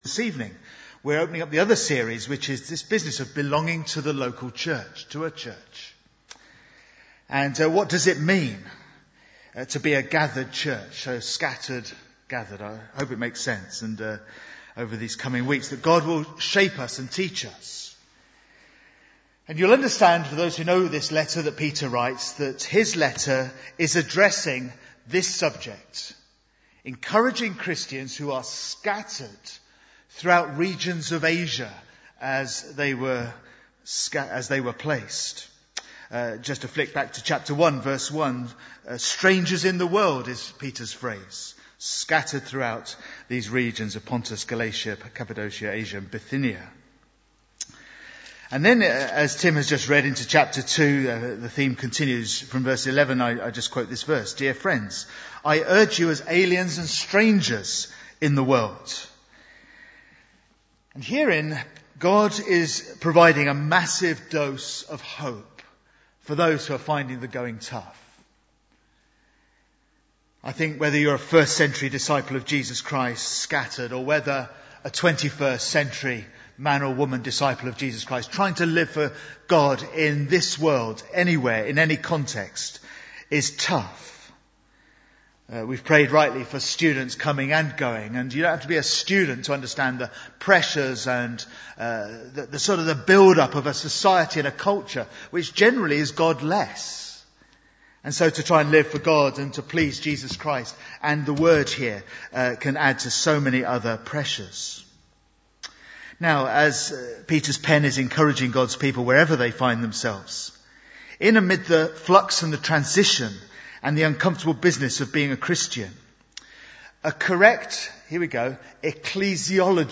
Bible Text: 1 Peter 2:4-12 | Preacher